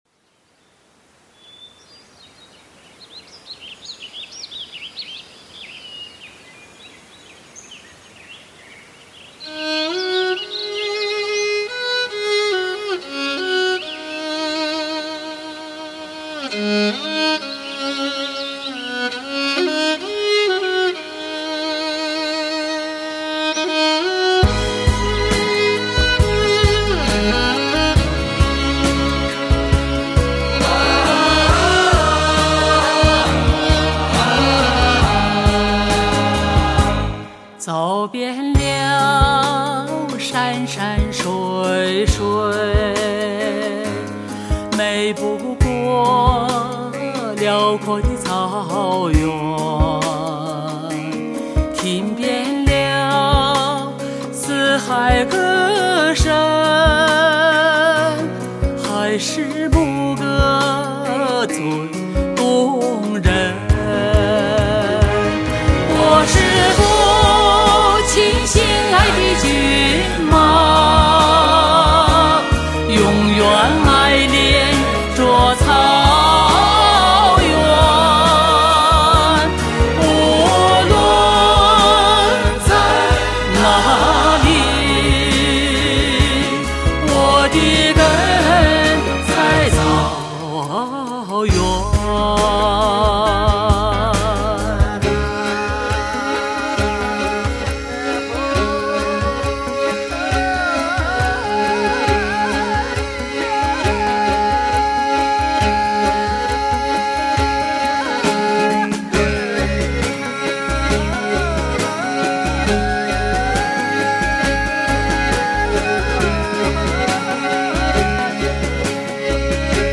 中国第一女中音歌唱家浓情献唱
"感人至深"唱给草原的牧歌
蒙古唱腔与美声唱法的极致融合